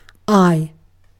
Ääntäminen
US : IPA : [aɪ]